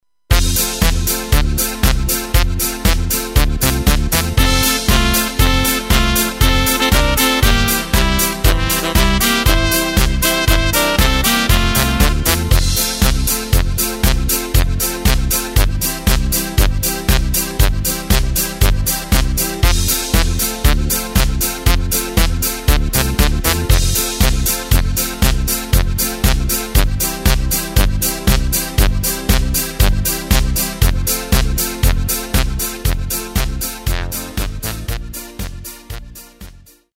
Takt:          2/4
Tempo:         118.00
Tonart:            F
Polka aus dem Jahr 1985!
Playback mp3 Demo